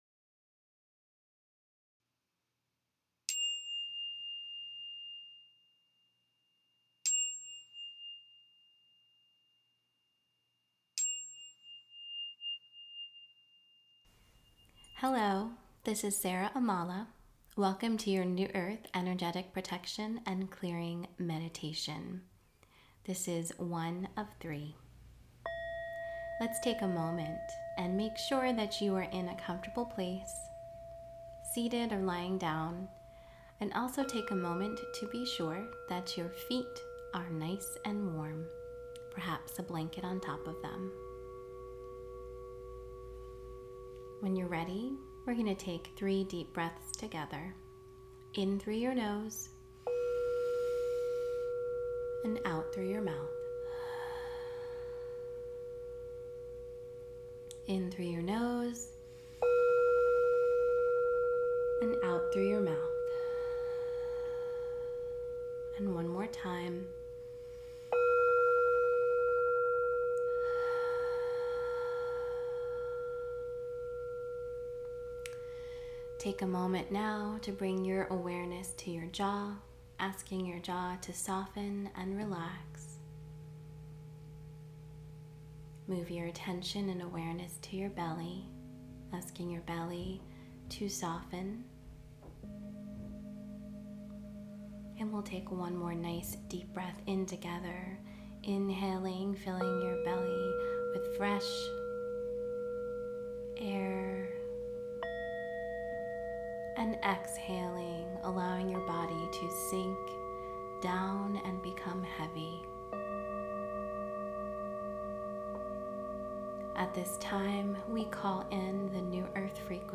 Below you will find today’s meditation. It’s wonderful to do this meditation before bed, but it’s fine to do it any time after your session.